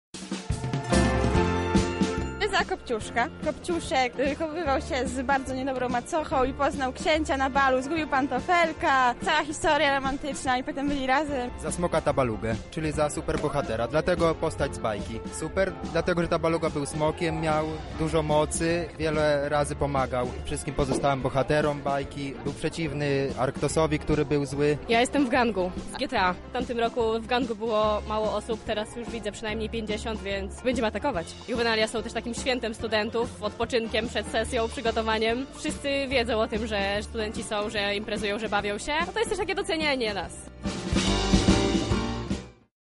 Ulicami Lublina przeszedł barwny korowód, który rozpoczął Dni Kultury Studenckiej.
Podczas korowodu uczestnicy tańczyli i śpiewali studenckie przyśpiewki.
korowód.mp3